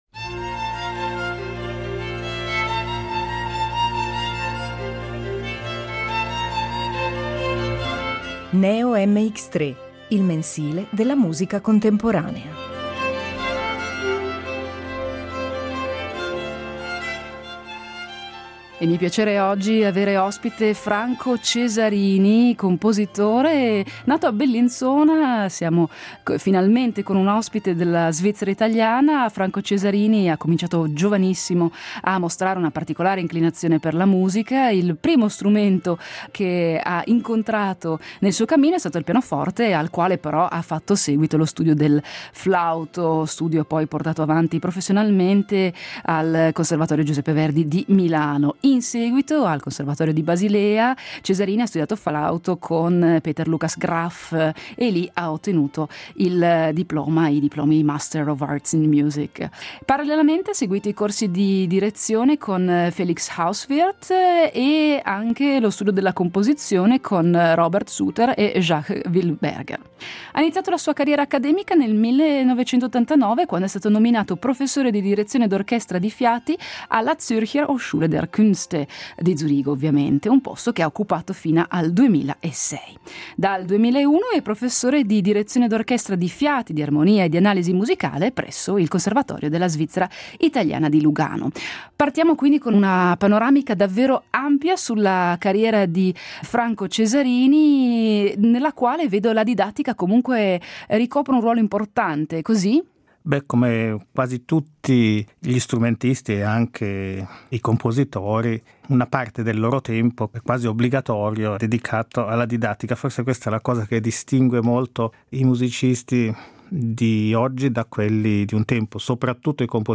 NEO.mx3 Intervista